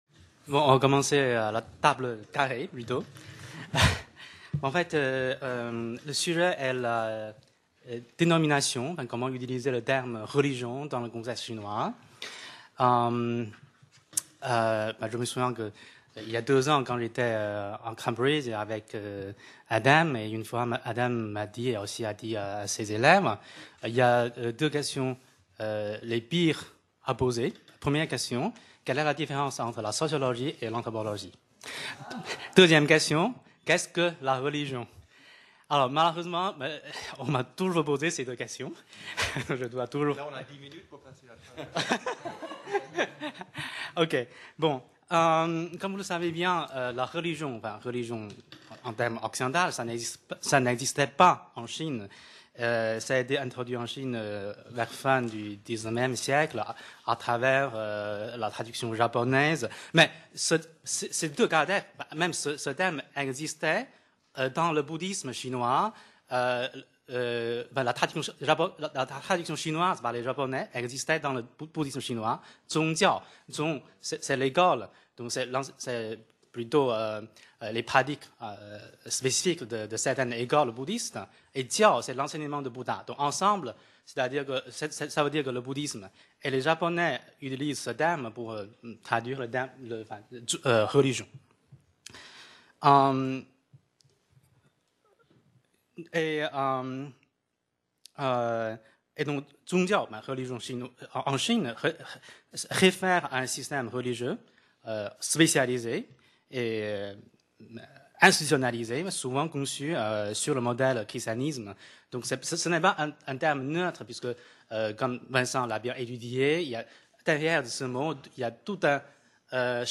Colloque international du 18 au 20 mai 2016 au CNRS site Pouchet, Paris 17e et à l'INALCO, Paris 13e. Table ronde : religion chinoise : du bon usage des dénominations